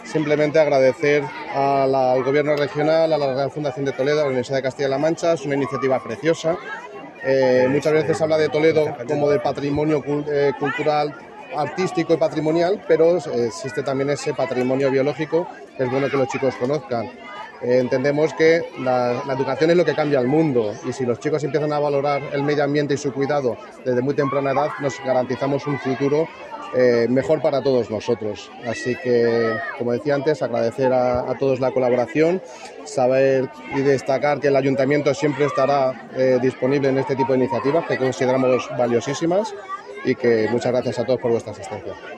Morcillo ha hecho estas declaraciones este viernes durante la plantación, en el colegio Fábrica de Armas de un clon de un laurel de Gustavo Adolfo Bécquer, que el poeta sembró en 1868 en su casa de Toledo.